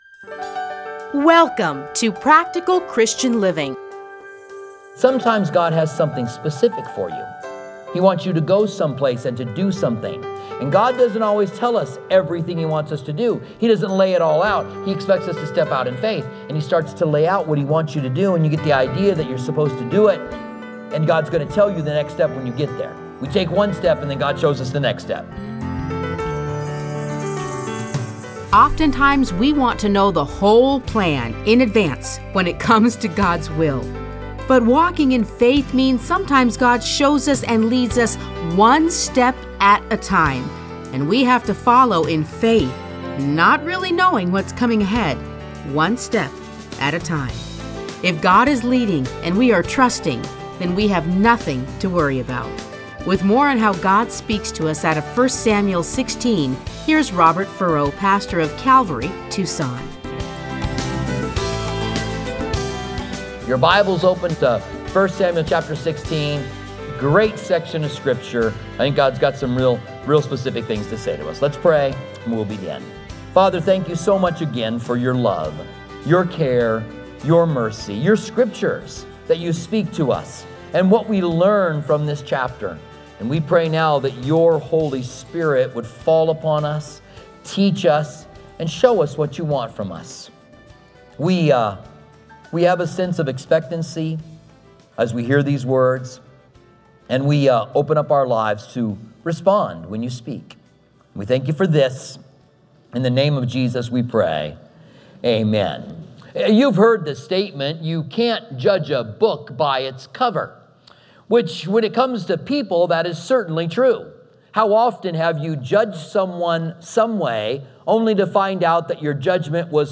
Listen to a teaching from 1 Samuel 16:1-23.